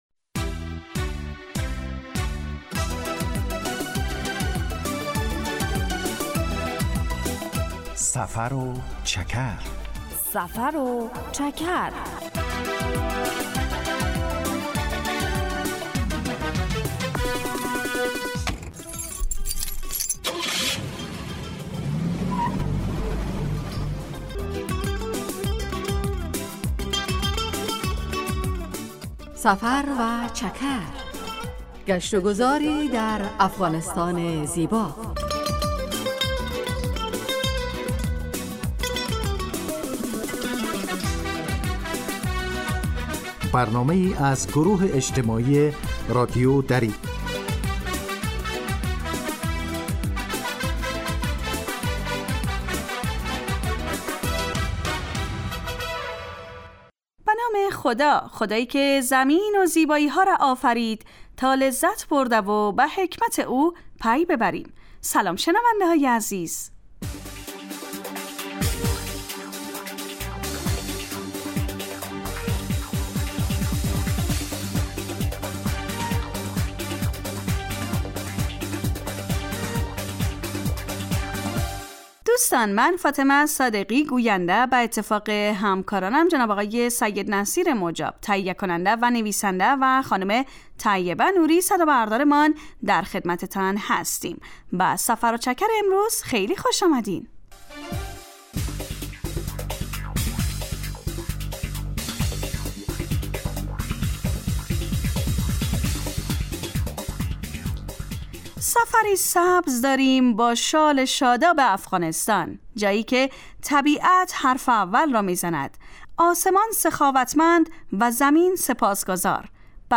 سفر و چکر برنامه ای 15 دقیقه از نشرات رادیو دری است که به معرفی ولایات و مناطق مختلف افغانستان می پردازد.
در این برنامه مخاطبان با جغرافیای شهری و فرهنگ و آداب و سنن افغانی آشنا می شوند. در سفر و چکر ؛ علاوه بر معلومات مفید، گزارش و گفتگو های جالب و آهنگ های متناسب هم تقدیم می شود.